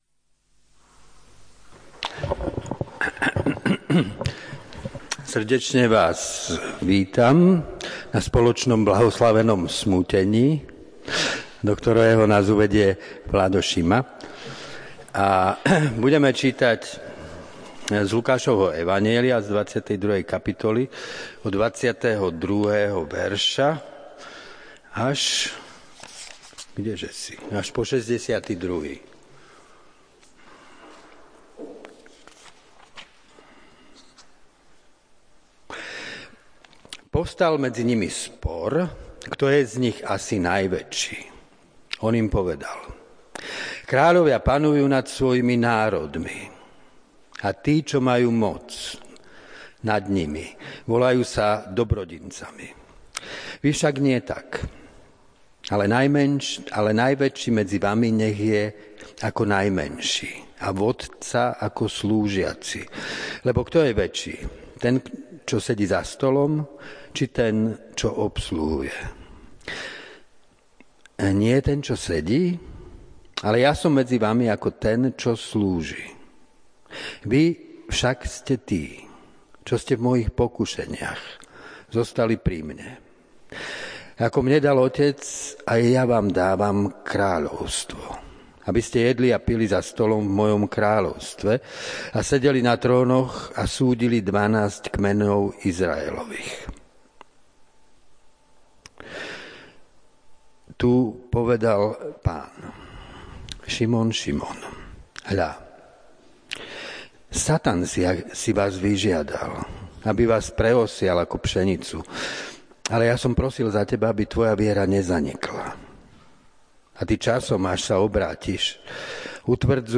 Nižšie nájdete nahrávky kázní, vypočuť si ich môžete aj vo forme podcastov (CBBA :: Bohoslužby a CBBA :: Homílie) alebo aj ako videonahrávky na našom youtube kanáli.
26-33 Podrobnosti Kázeň Prehliadač nepodporuje prehrávač.